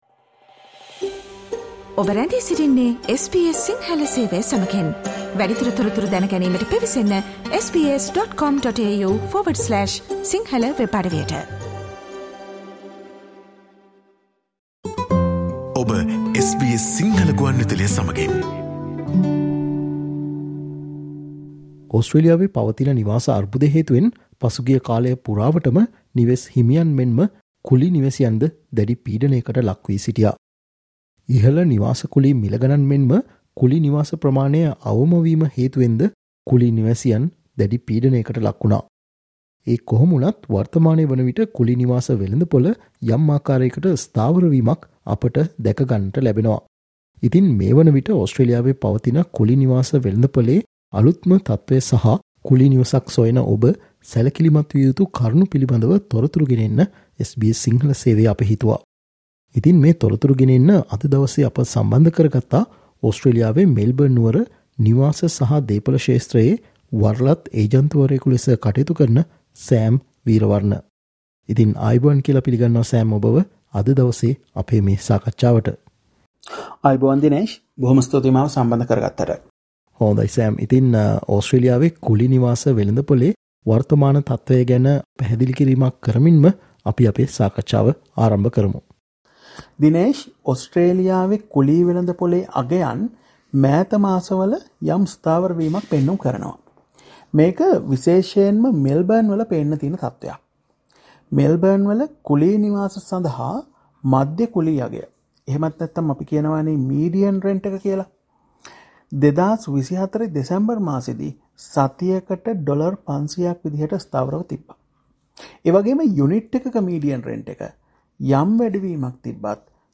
However the rental market is softening right now bringing some relief for renters. Listen to SBS Sinhala discussion on the current state of the rental market in Australia.